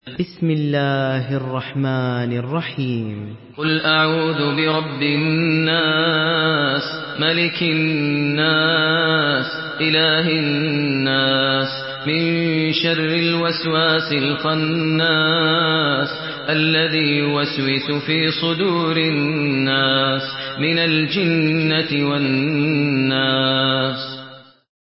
سورة الناس MP3 بصوت ماهر المعيقلي برواية حفص
مرتل